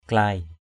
/klaɪ/ 1.